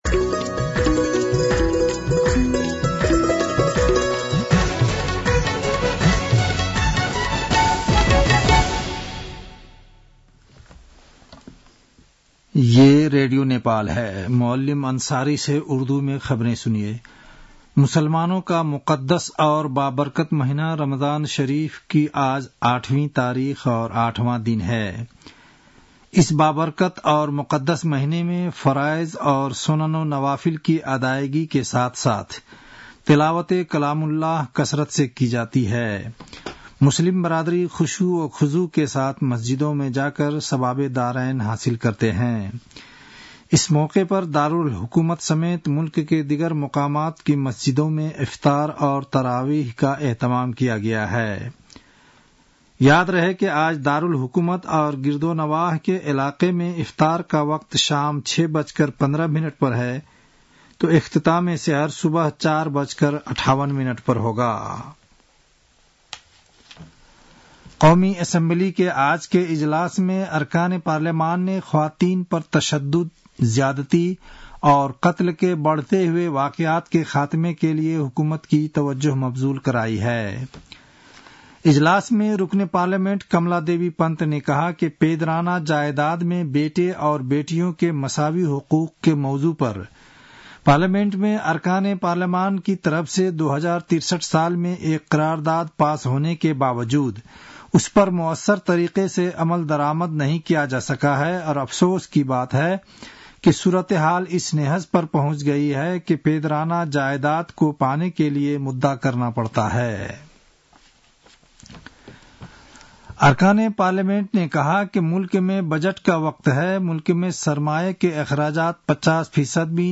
उर्दु भाषामा समाचार : २६ फागुन , २०८१